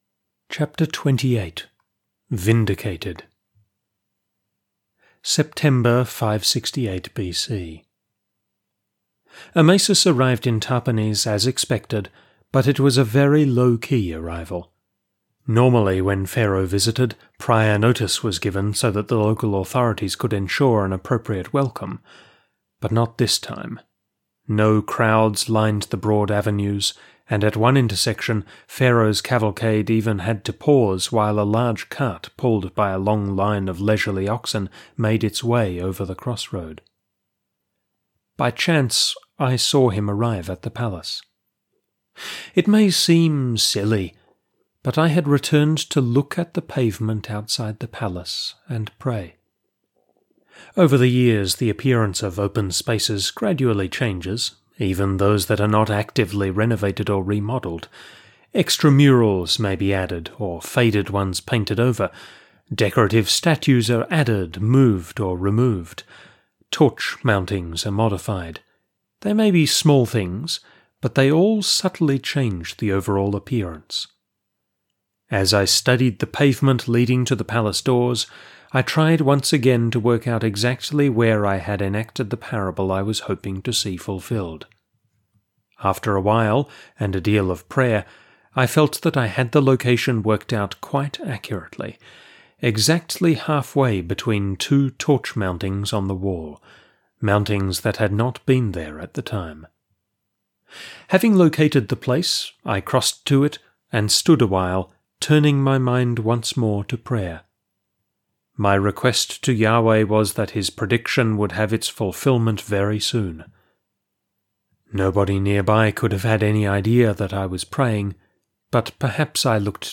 This week we approach the end of our audiobook serialisation of That Broken Reed. Jeremiah is hoping for the fulfilment of a particular prophecy – one made soon after the refugees from Judah had made their way to Tahpanhes.